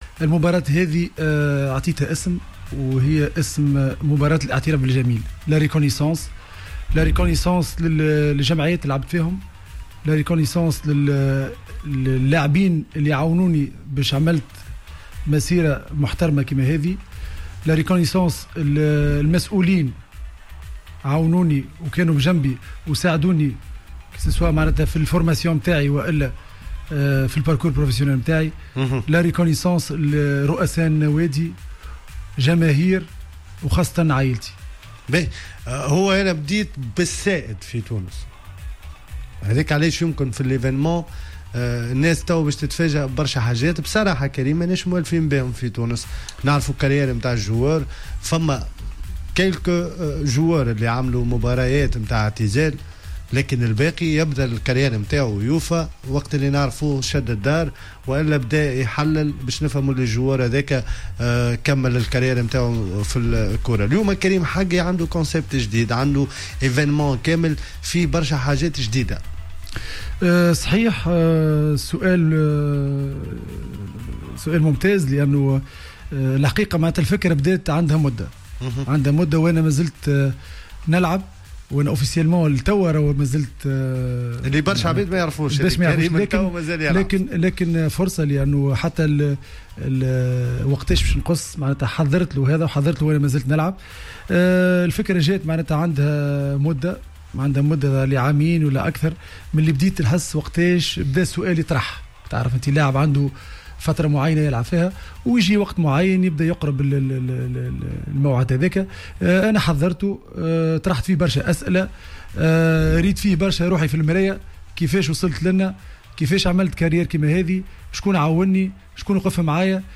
إستضافت حصة "Planète Sport" اللاعب الدولي السابق كريم حقي الذي أعلن للمرة الأولى عن التفاصيل الخاصة بمباراة إعتزاله كرة القدم و الذي أطلق عليها مباراة "الإعتراف بالجميل".